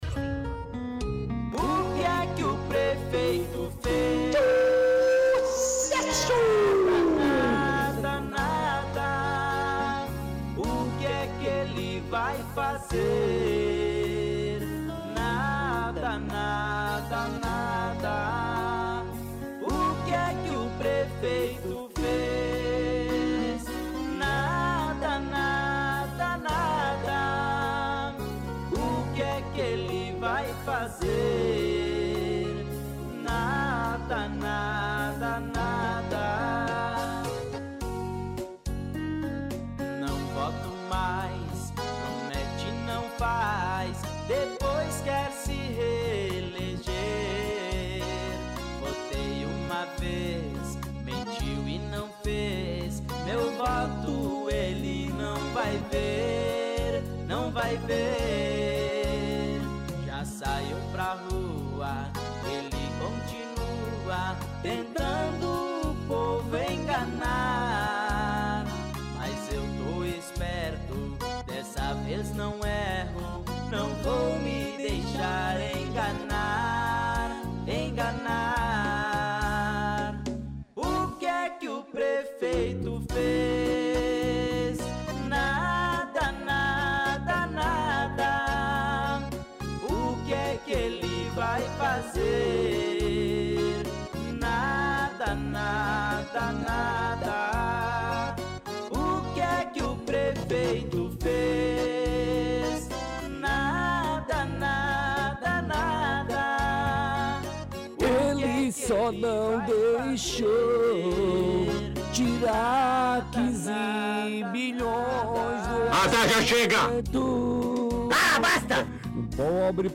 – Música “O que que o prefeito fez”.